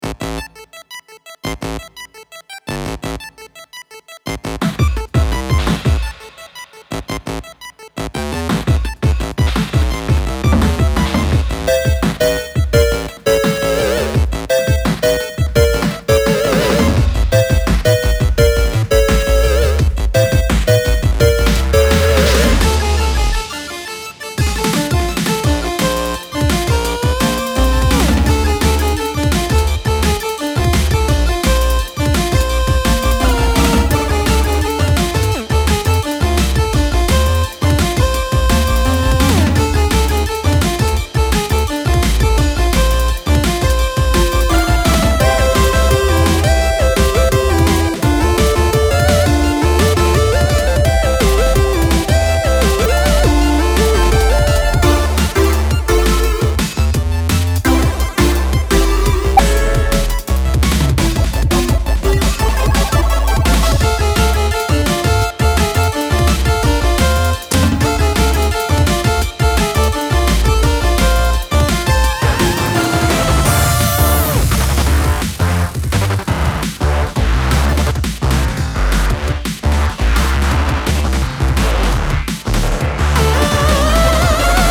groovy chiptune DnB combo with live drums
vocoded voices